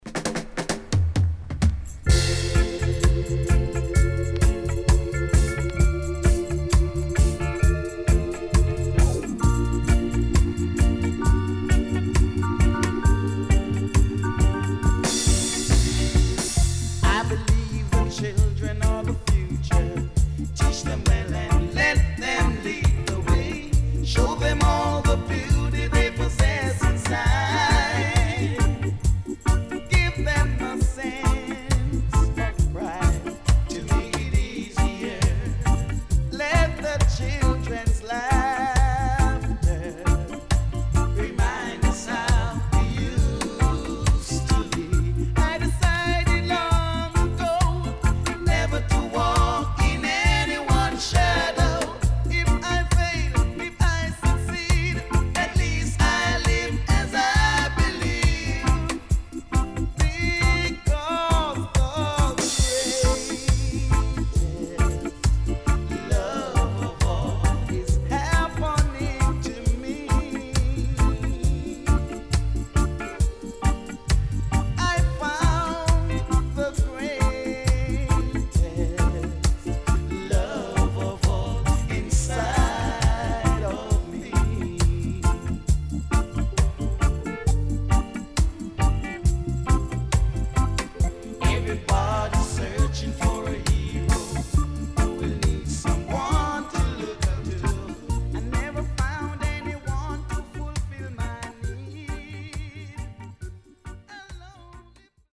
SIDE B:少しチリノイズ入ります。